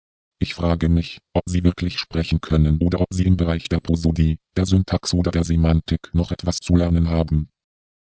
Bsp11 nur mit Phrasenkommandos,